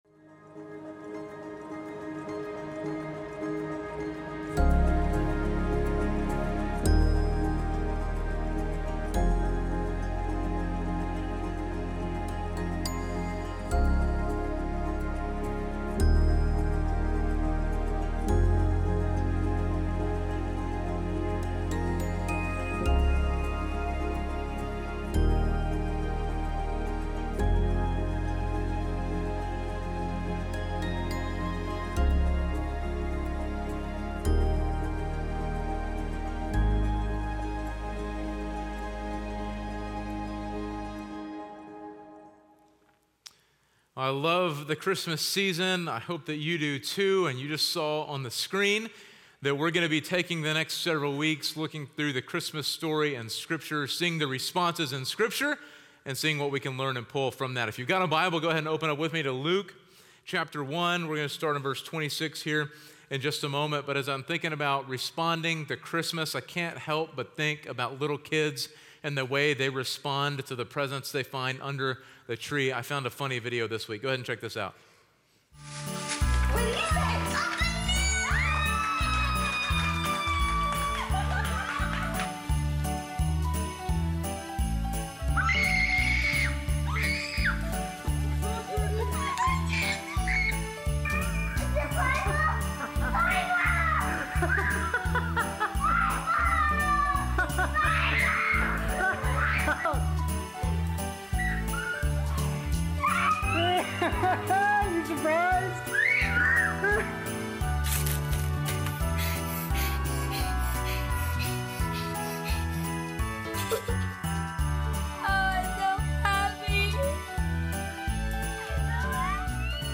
Our current sermon series, Priorities, is a call to realign our lives around what matters most to God.